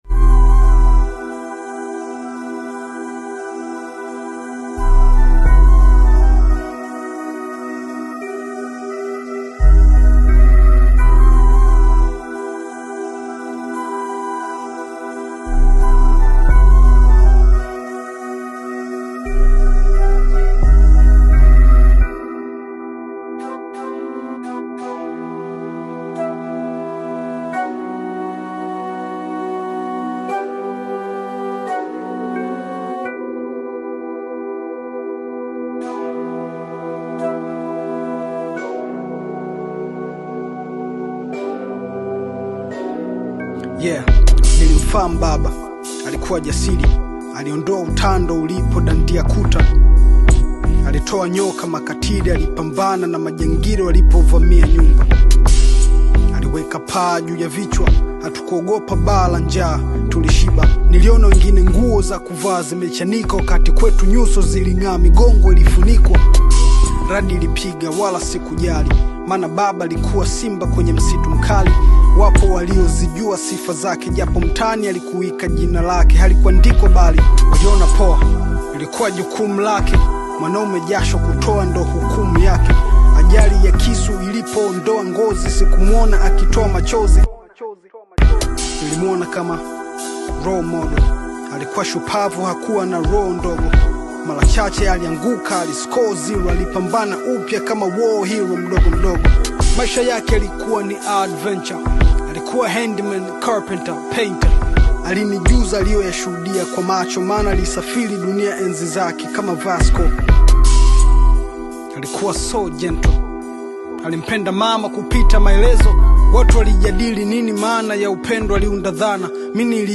Hip-hop fans